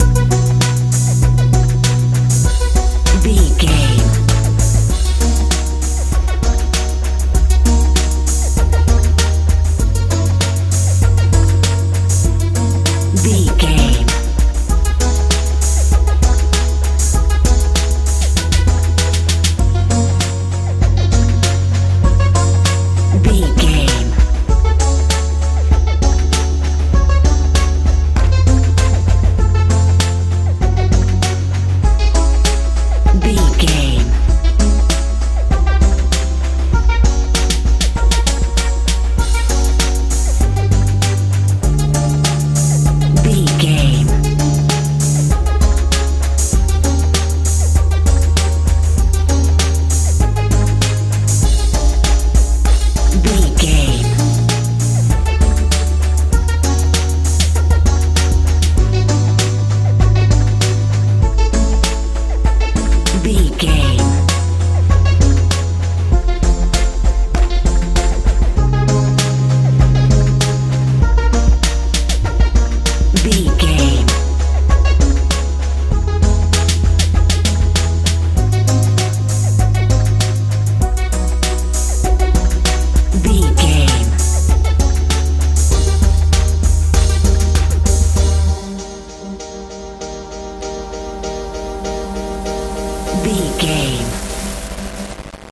modern dance
Ionian/Major
magical
futuristic
bass guitar
drums
synthesiser
suspense
tension